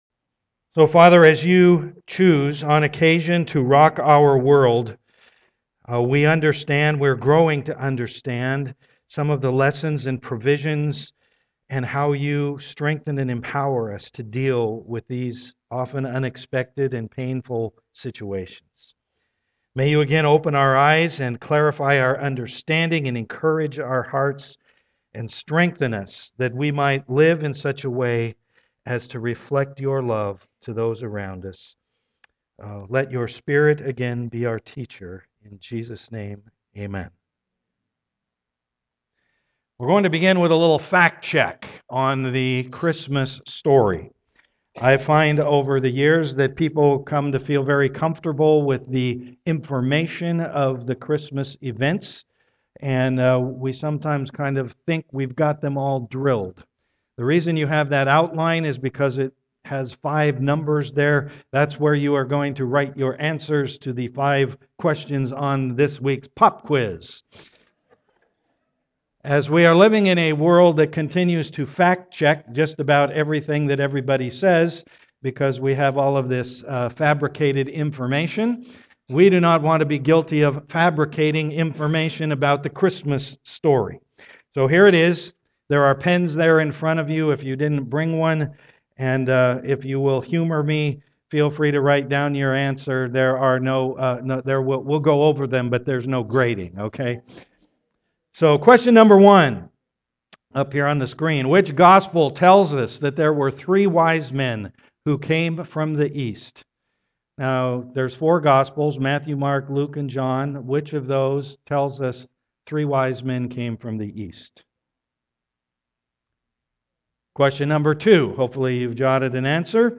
Rock My World Passage: Luke 1:26-56 Service Type: am worship Discussion questions found on "bulletin" link below.